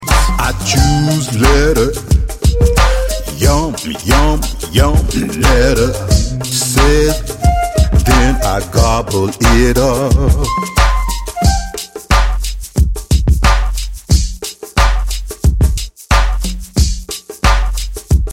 Listen to the open version of this song.